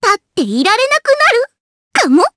Rehartna-Vox_Skill2_jp_b.wav